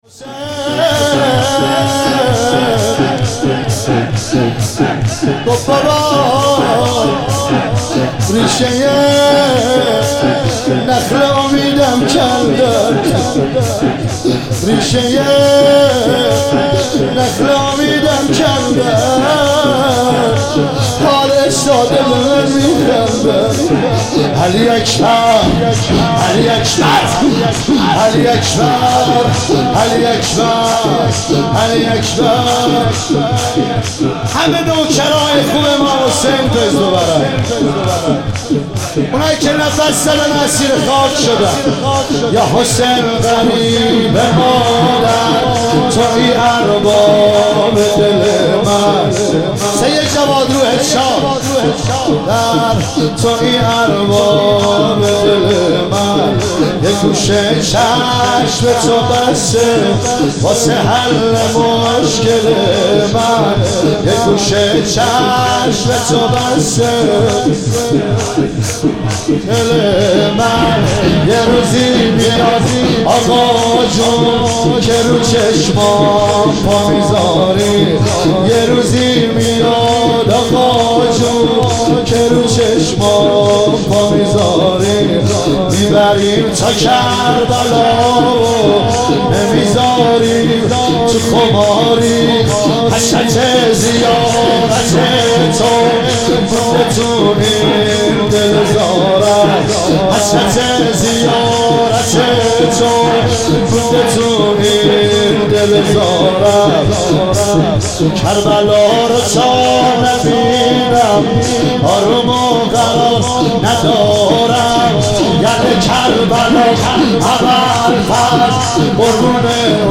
شب هشتم محرم 1398 – ساری
شور یاحسین غریب مادر تویی ارباب دل من